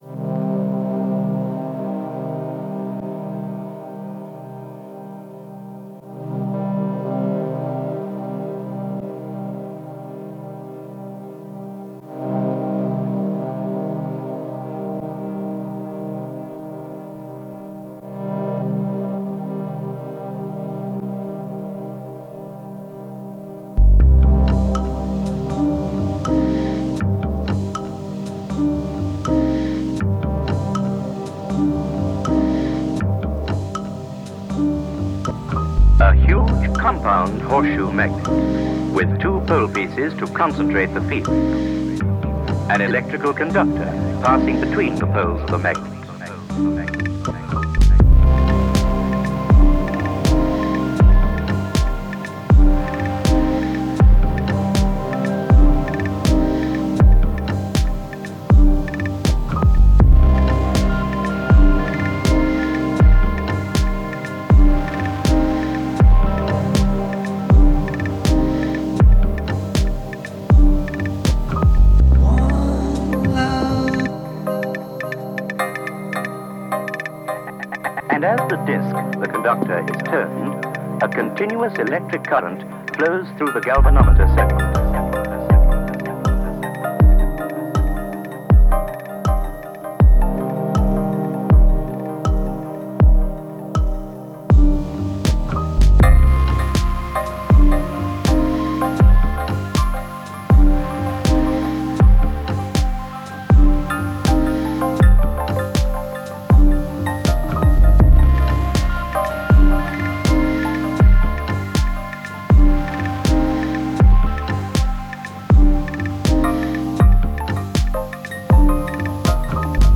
Sampling multi-bar loops from the DN2 perfectly synced to the project is an absolute breeze.
I combined that raw material with samples from the SD card and sequenced everything within the M8.
The mix feels a bit muffled compared to my previous tracks, I definitely still have some progress to make on that front!